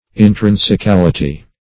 Search Result for " intrinsicality" : The Collaborative International Dictionary of English v.0.48: Intrinsicality \In*trin`si*cal"i*ty\, n. The quality of being intrinsic; essentialness; genuineness; reality.